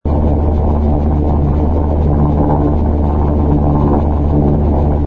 engine_li_fighter_loop.wav